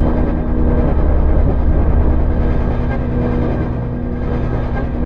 conjuration-magic-sign-circle-loop.ogg